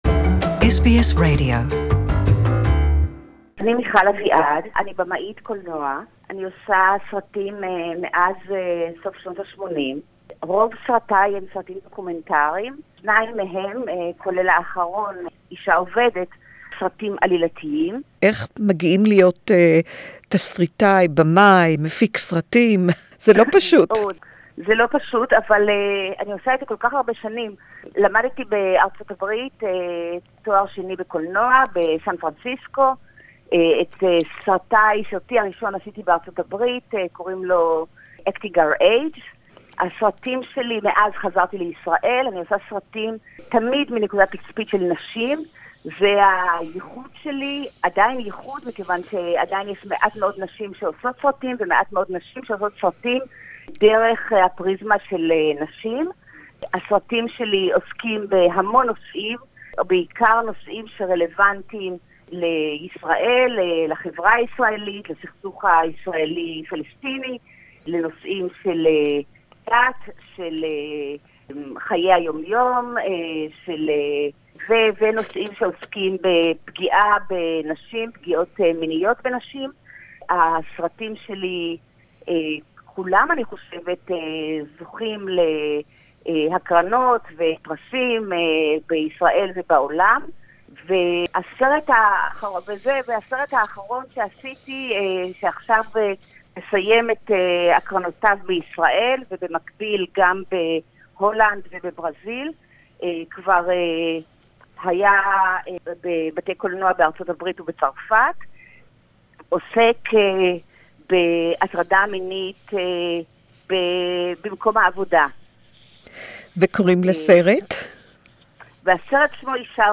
Sexual harassment at the work place...See the Israeli movie "Working Woman" listen to our interview in Hebrew with the film maker Michal Aviad